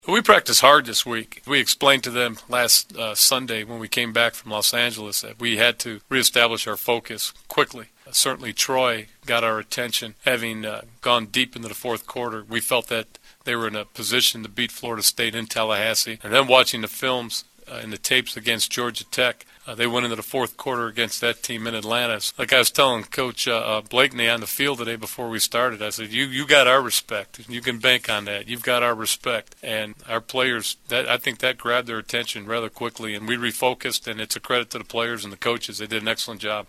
The following are audio links to postgame interview segments with Husker players and coaches after Nebraska's 56-0 win over Troy.
Head Coach Bill Callahan